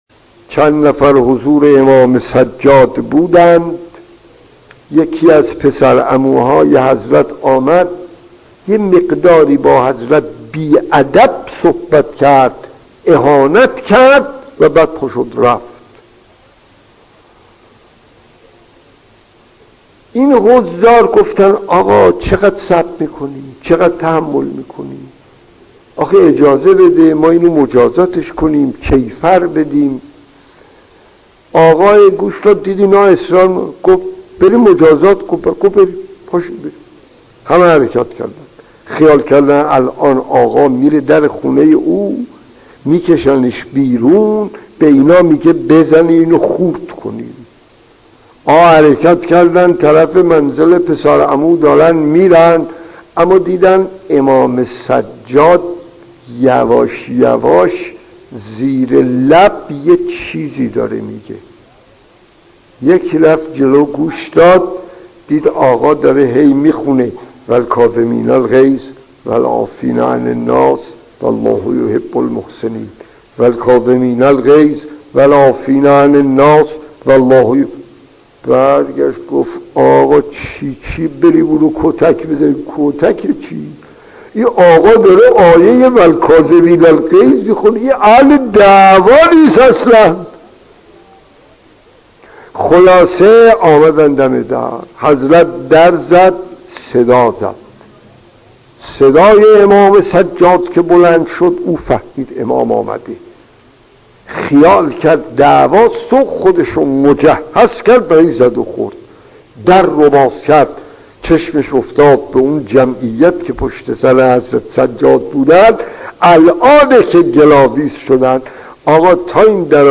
داستان 20 : امام سجاد و مرد پرخاشگر خطیب: استاد فلسفی مدت زمان: 00:03:27